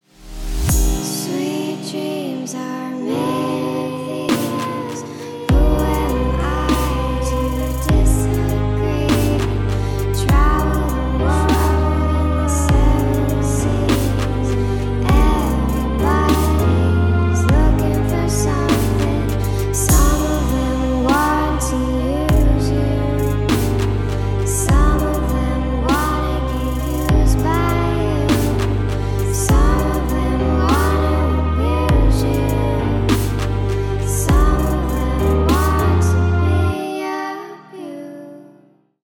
Ремикс
спокойные